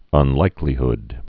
(ŭn-līklē-hd)